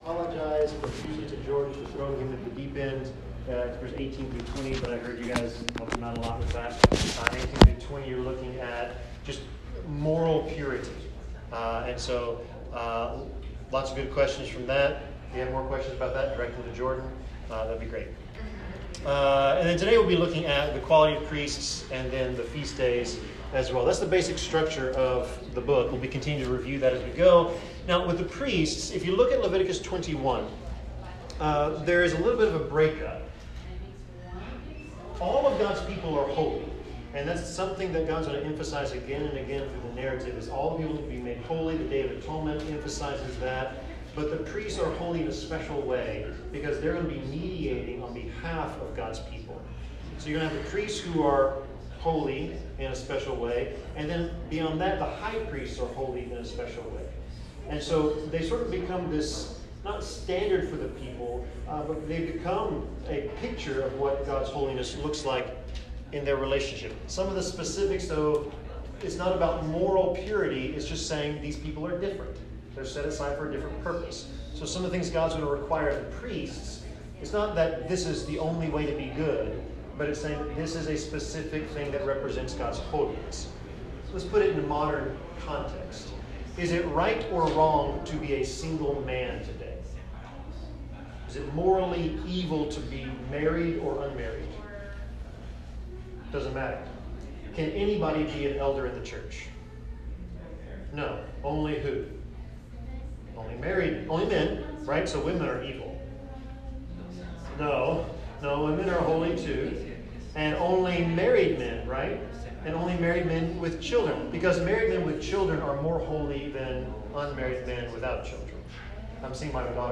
Leviticus 21-23 Service Type: Bible Class God expects His people to reflect His character and holiness in all areas of life.